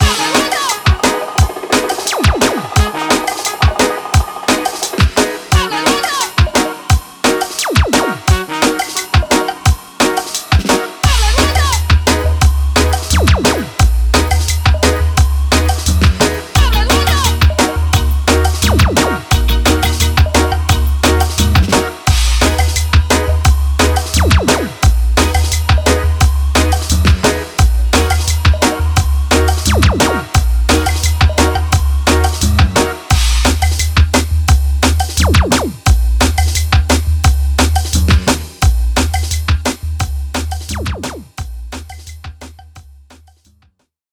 Genre:Jungle
ここでは、ハイオクタンなジャングルとドラム＆ベースが融合します。
このパックには、力強いビート、ダイナミックな要素、メロディックなレイヤーが揃っており、あなたの制作をさらに加速させます。
ドラム：力強いドラムグルーヴ、複雑なブレイクビート、レイヤーやカスタマイズが可能な個別ドラムパート
ホーンズ＆スカンクス：エネルギッシュなブラスリフとシャープでリズミカルなスカンクスが、レゲエ風の味わいを加えます
ミュージックコンボ：シンセ、パッド、レイヤードテクスチャを組み合わせたメロディックなコンビネーションで奥行きを演出
デモサウンドはコチラ↓